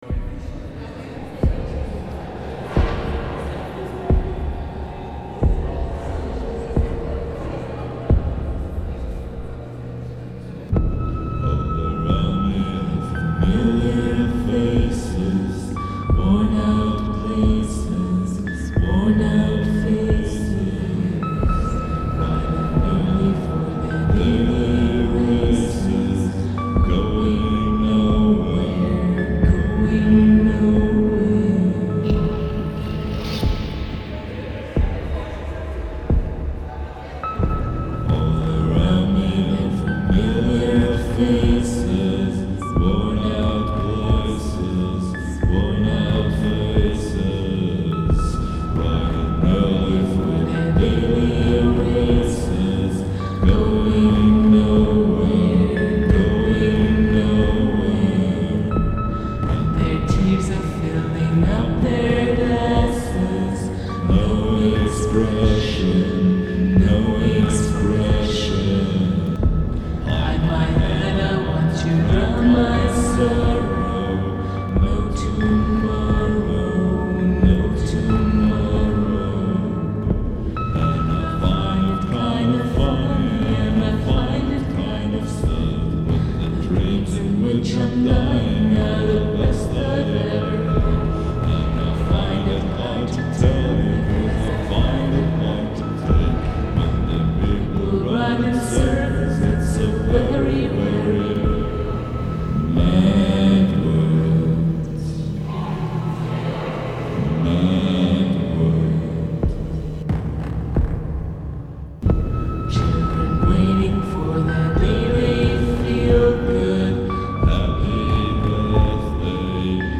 Cover, das schön düster ist (n bischen Dark Wave mit Piano)